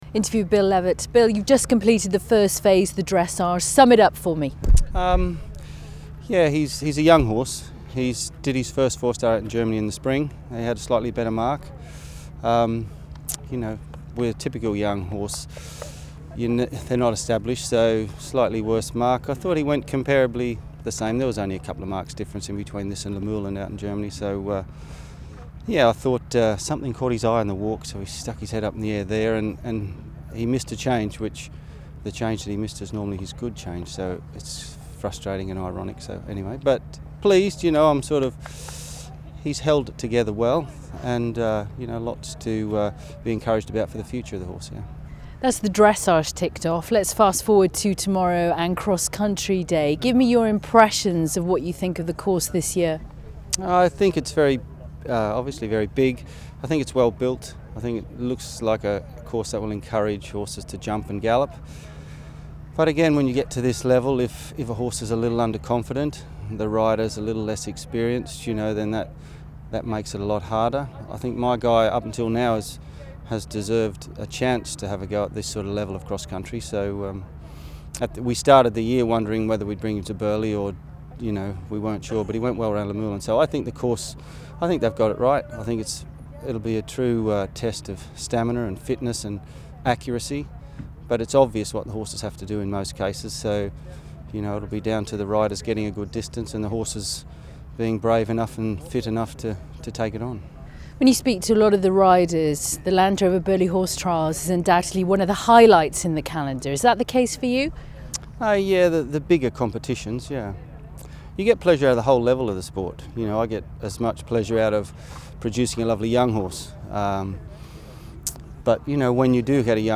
reaction post dressage at the Land Rover Burghley Horse Trials